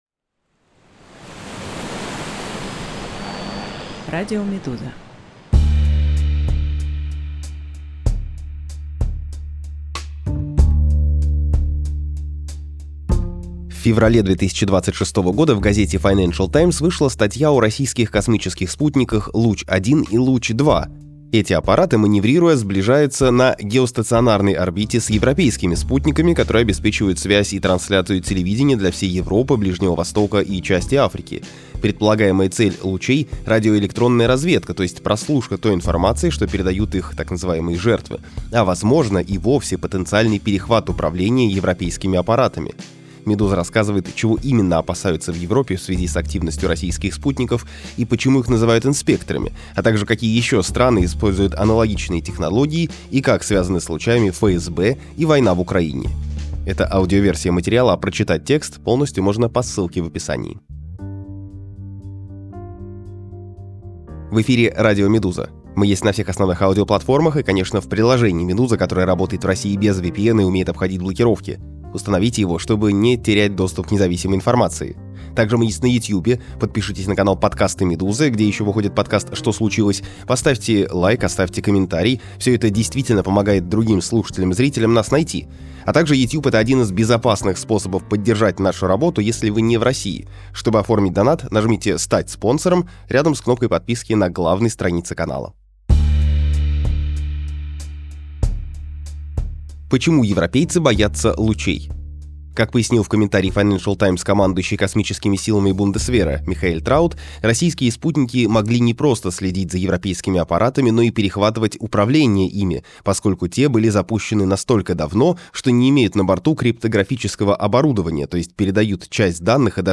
Аудиоверсию этого текста слушайте на «Радио Медуза» подкасты Россия совершает диверсии в космосе?